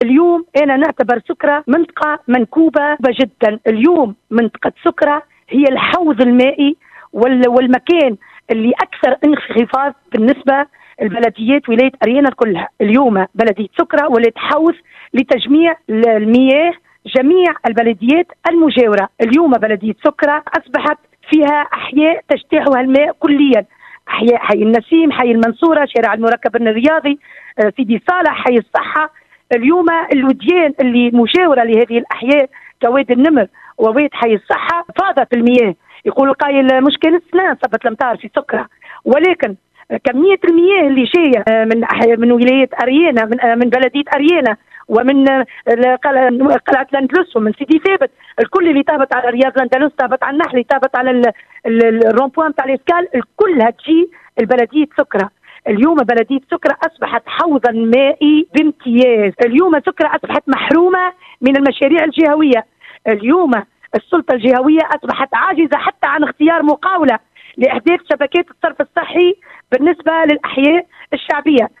« Aujourd’hui, la circonscription de la Soukra récolte toutes les eaux provenant de toute l’Ariana. Les différentes cités de la Soukra ont été envahies par les eaux, notamment les cités d’El Mansoura, Sidi Salah, Ennassim et Essaha. Aujourd’hui, les rivières avoisinantes ont débordé sur des cités », a-t-elle aussi indiqué dans une déclaration téléphonique accordée aujourd’hui sur Mosaïque FM.